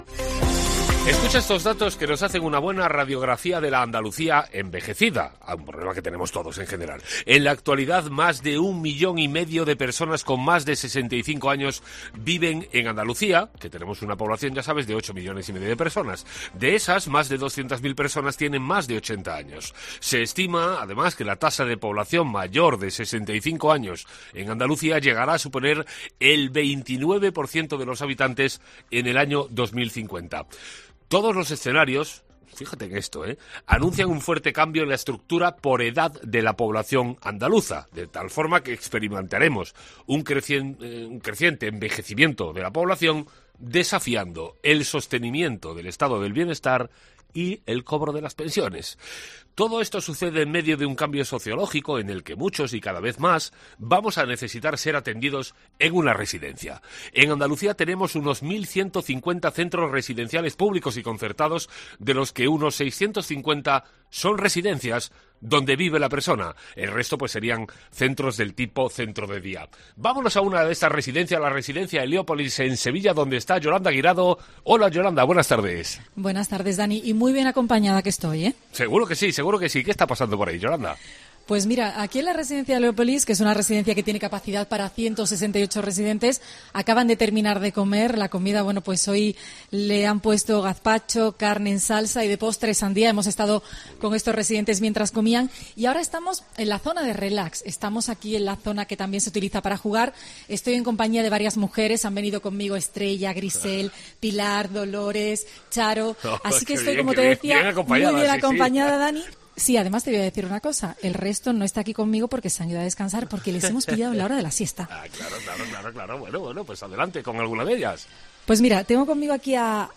Un equipo de COPE Andalucía entra en la residencia sevillana de Heliópolis para conocer cómo viven nuestros mayores en este centro financiado por la Junta de Andalucía